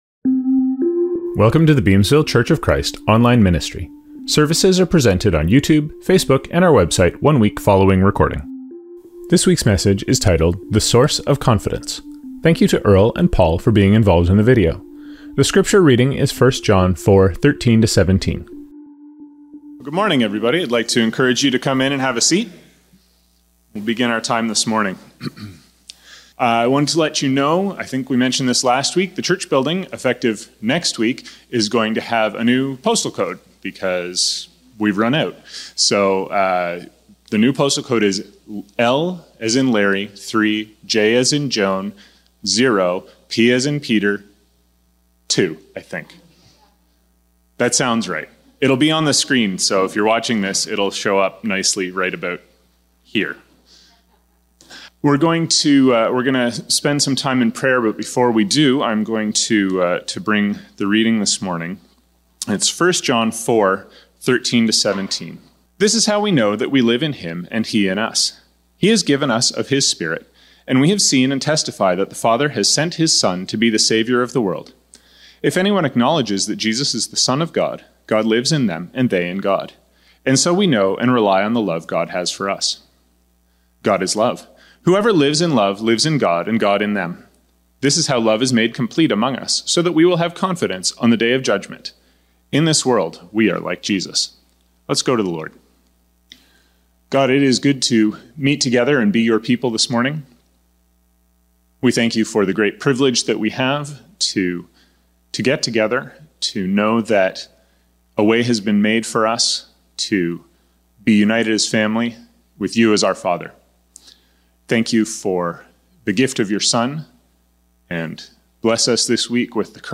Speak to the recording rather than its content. Songs from this service include: